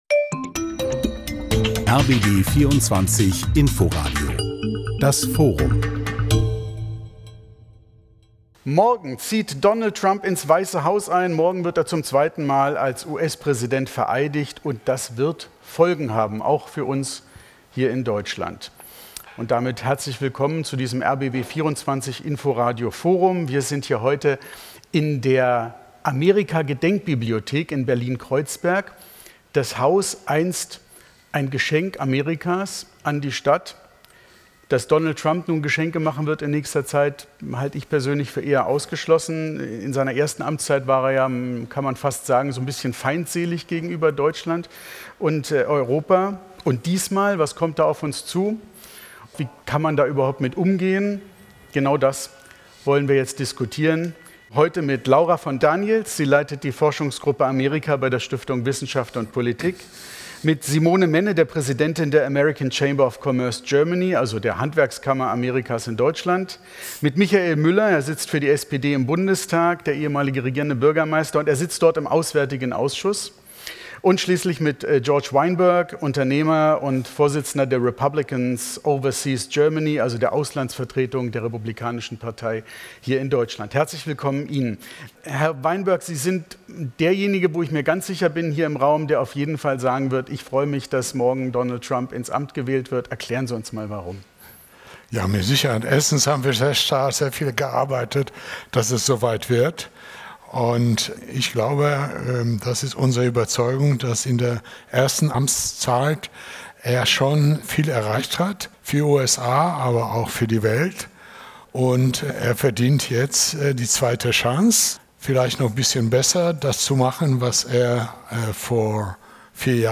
Die Veranstaltung ist eine Kooperation von rbb24 Inforadio mit der Zentral- und Landesbibliothek Berlin (ZLB).